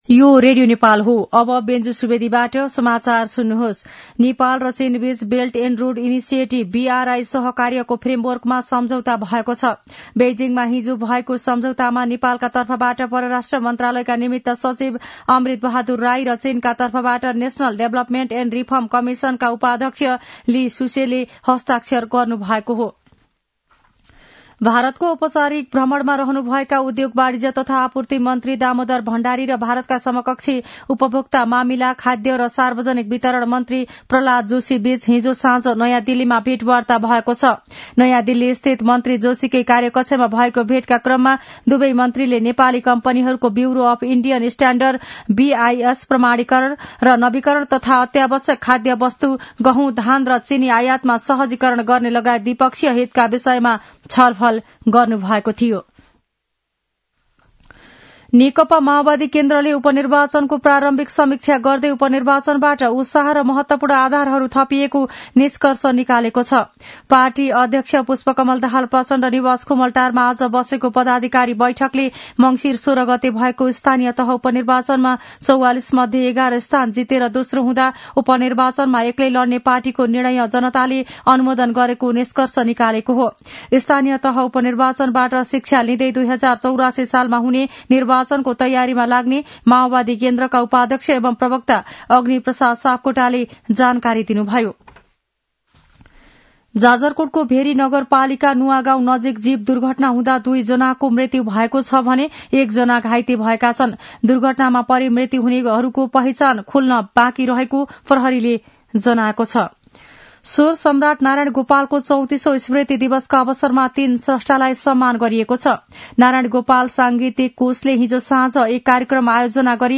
दिउँसो १ बजेको नेपाली समाचार : २१ मंसिर , २०८१
1-pm-nepali-news-1-4.mp3